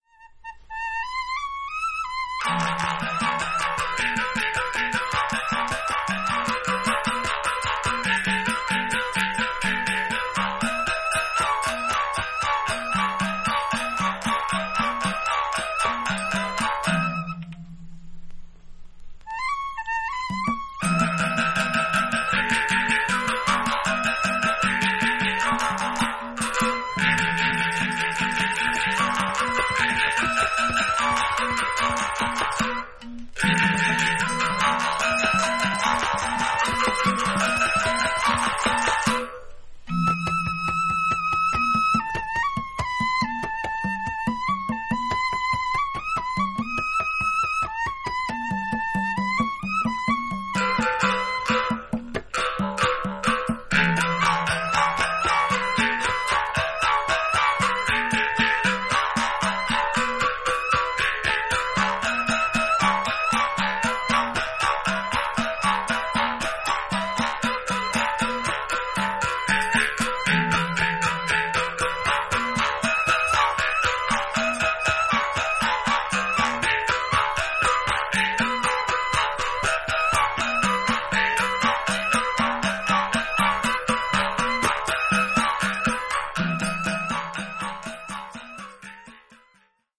バリの伝統的古典音楽をコンパイルしたオムニバス。
B1a Genggong Ensemble Of Ubud - Tabuh Tely